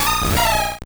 Cri d'Azumarill dans Pokémon Or et Argent.